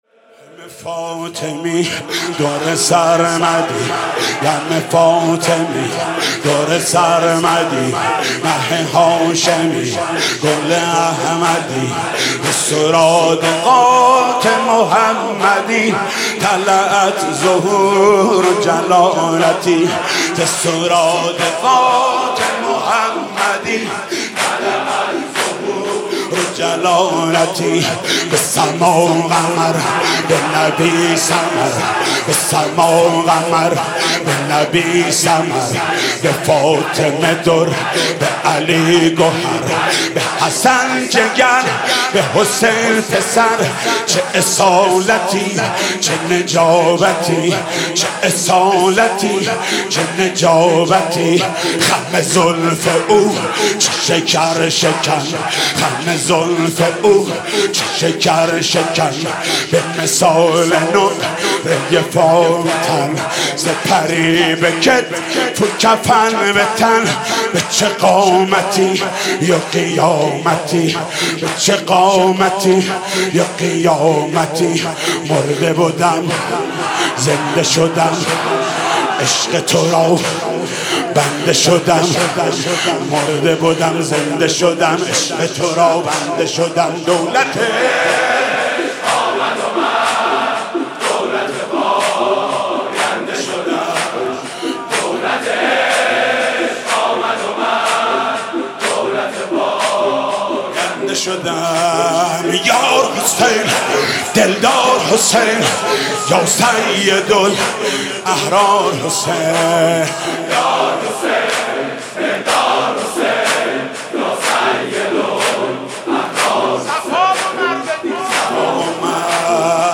مناسبت : شب هشتم محرم
قالب : زمینه